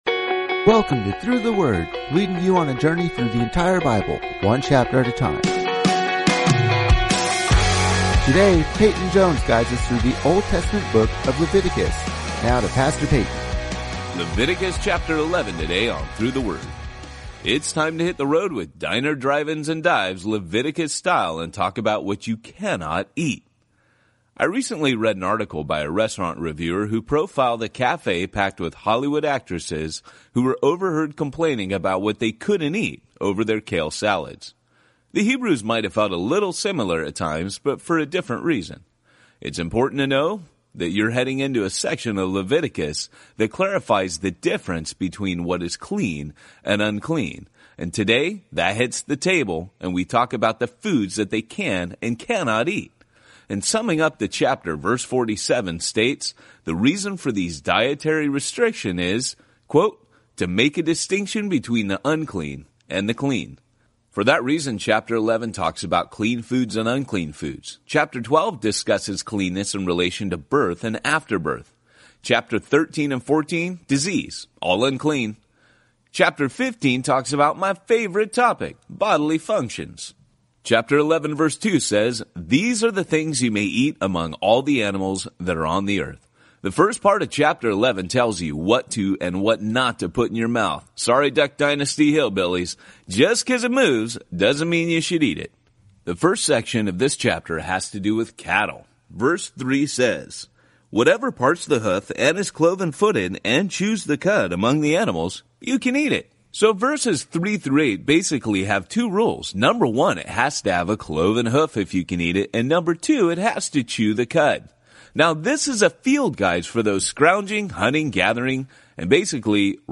19 Journeys is a daily audio guide to the entire Bible, one chapter at a time. Each journey takes you on an epic adventure through several Bible books as your favorite pastors explain each chapter in under ten minutes. Journey #4 opens the grand story of redemption in Exodus, Ephesians, and Philemon, then gets to the heart of law and grace in Peter, Leviticus, and Galatians.